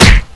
kungfuhit.wav